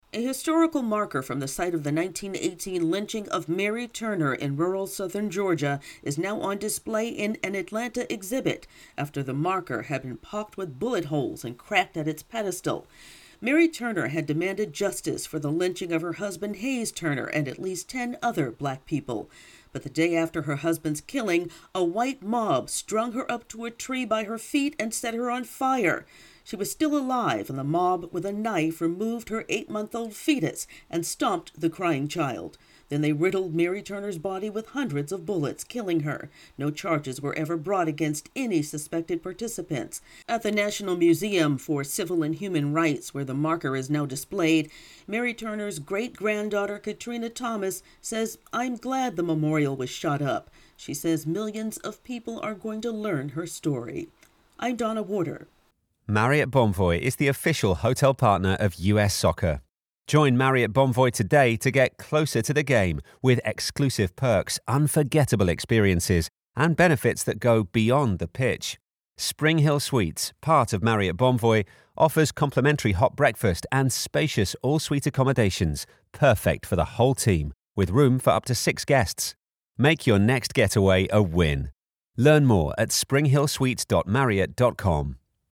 A historical marker on display in an Atlanta museum opens to the public on Monday. AP correspondent